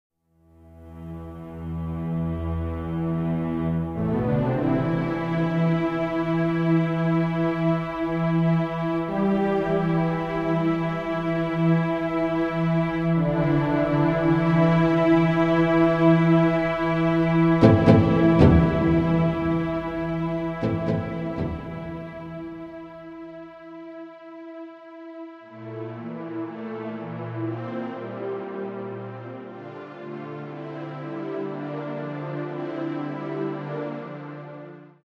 Sound Track
SYNTHESIZER